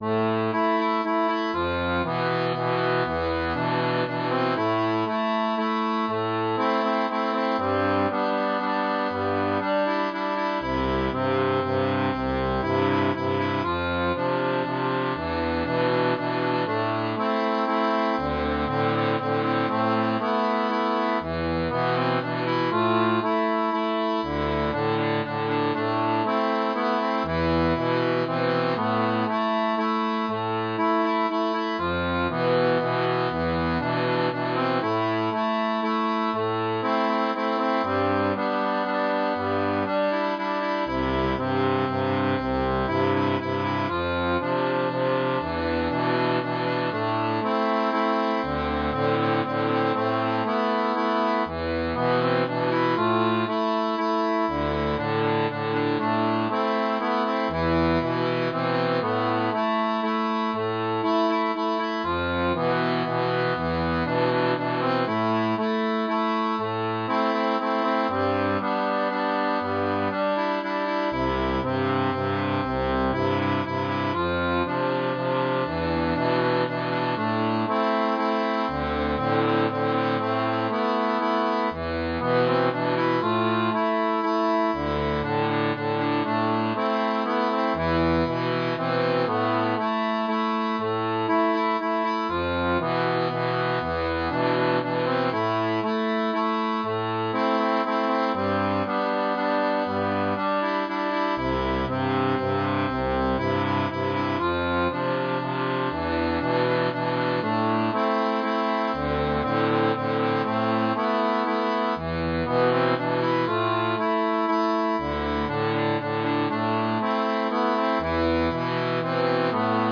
Type d'accordéon
Folk et Traditionnel